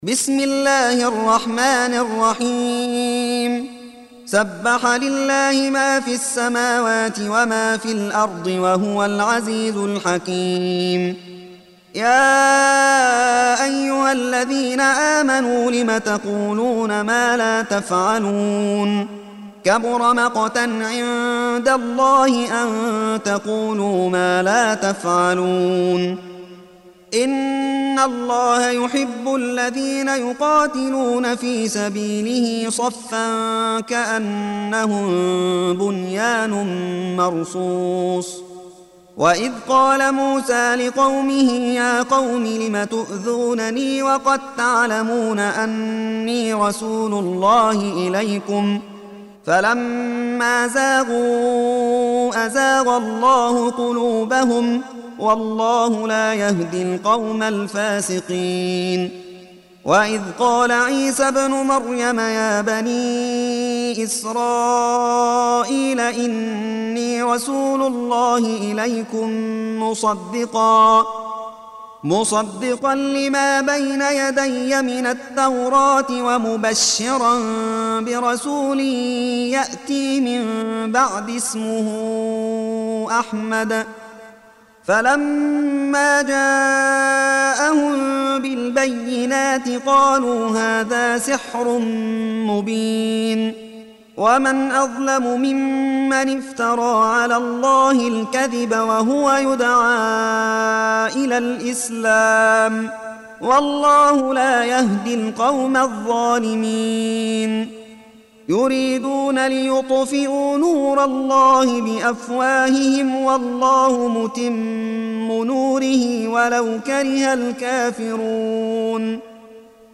Surah Sequence تتابع السورة Download Surah حمّل السورة Reciting Murattalah Audio for 61. Surah As-Saff سورة الصف N.B *Surah Includes Al-Basmalah Reciters Sequents تتابع التلاوات Reciters Repeats تكرار التلاوات